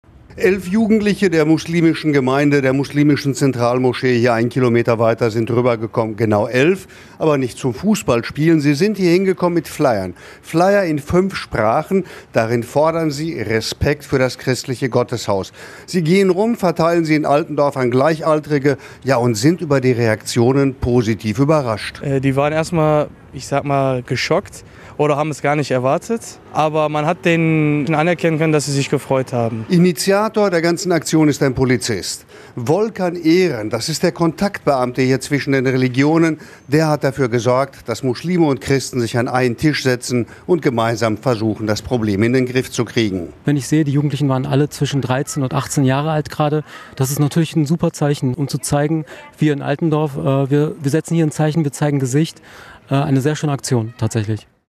Stadtreporter trifft Jugendliche an der Kirche